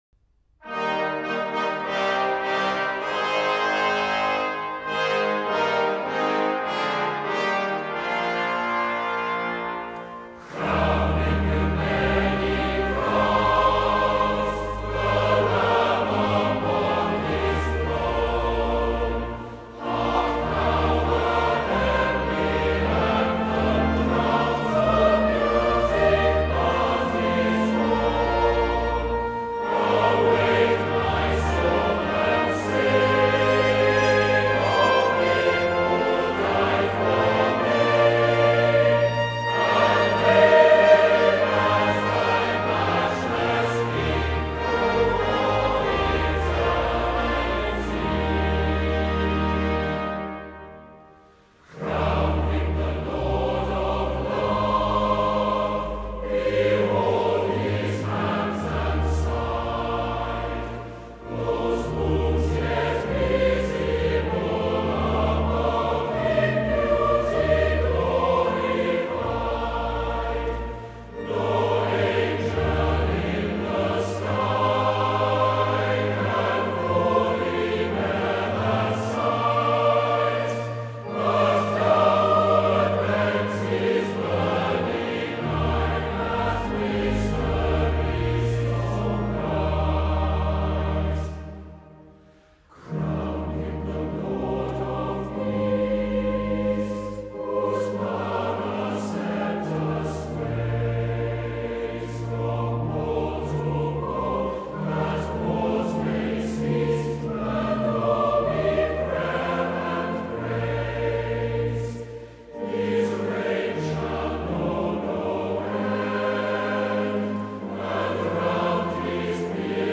A triumphant hymn of praise and coronation
majestic tune